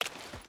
Water Walk 3.ogg